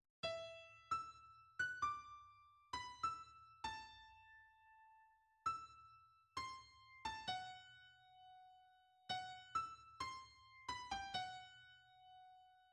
Allegro non troppo
Main theme (mm. 2–4)